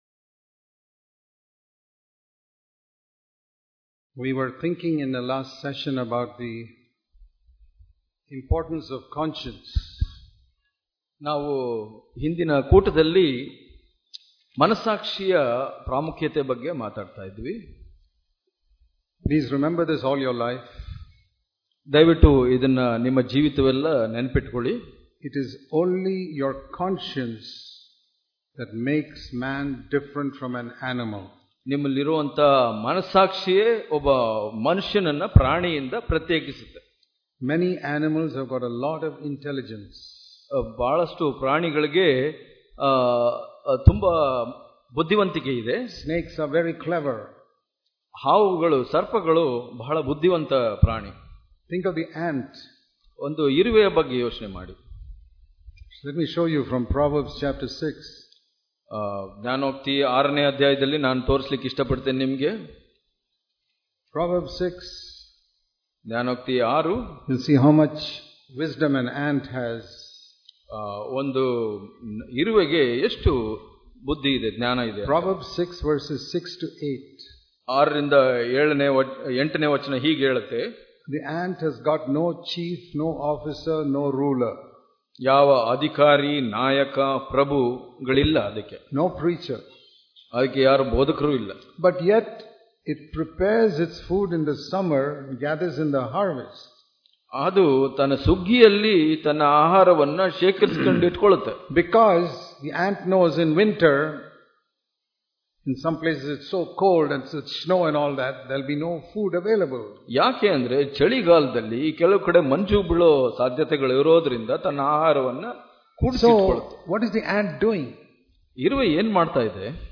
Hubli Conference 2018